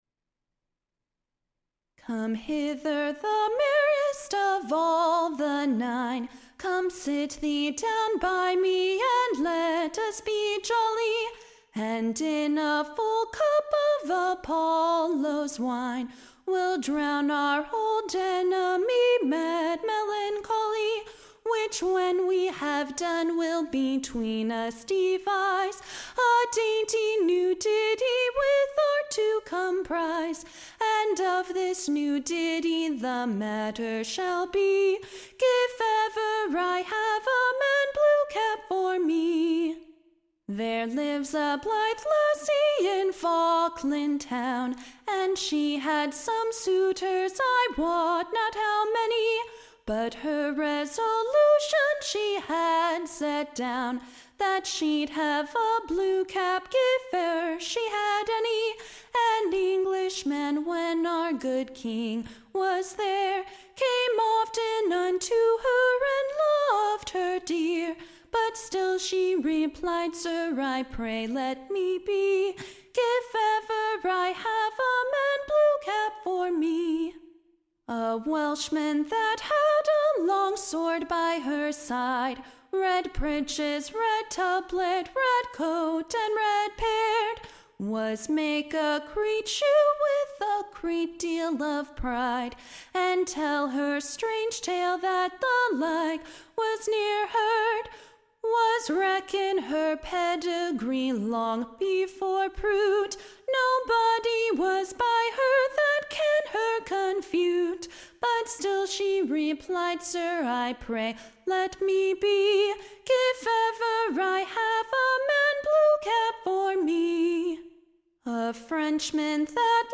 Tune Imprint To a curious new Scottish tune called Blew-cap.